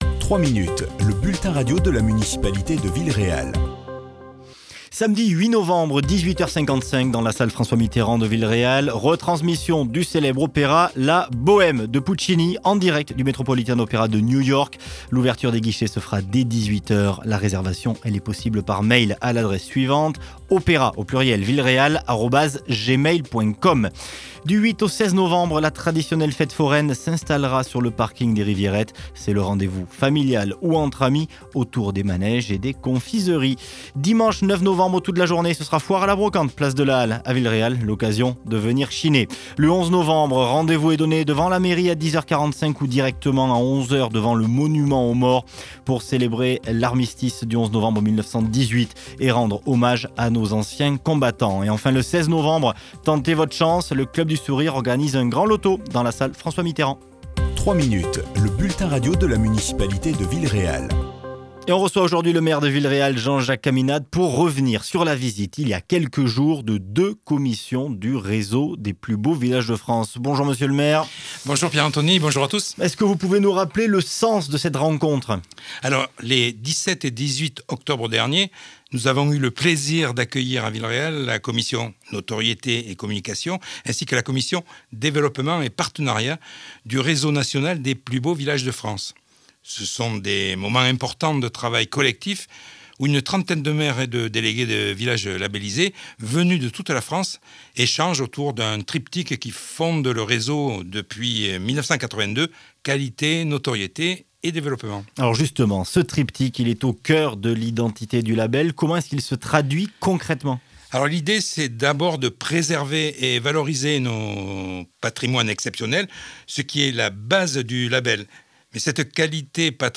Le maire Jean-Jacques Caminade revient sur la visite, il y a quelques jours à Villeréal, d'une importante délégation de l’association « Plus Beaux Villages de France » à l’occasion d’un week-end de travail dans la bastide.